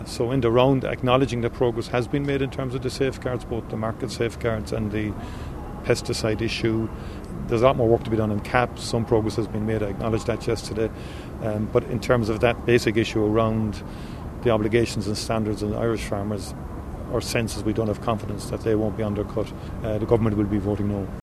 Speaking in China, Micheál Martin told reporters that Ireland will vote no…………………